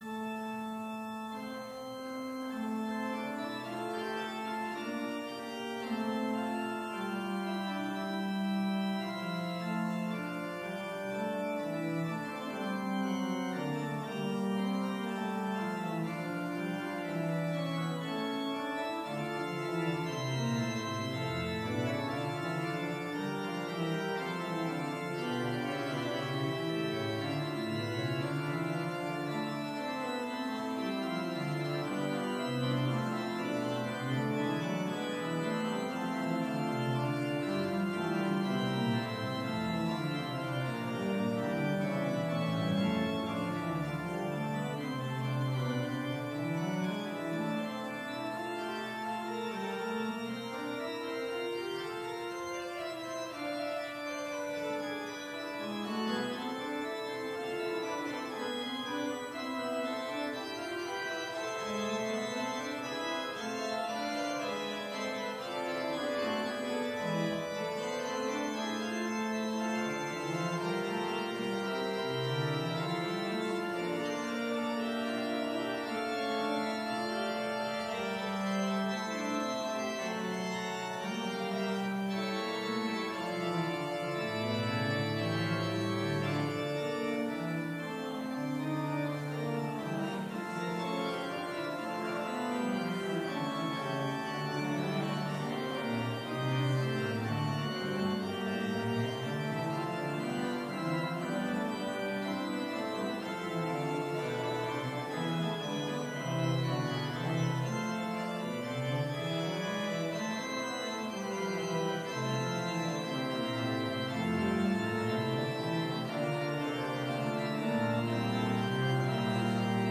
Complete service audio for Chapel - March 25, 2019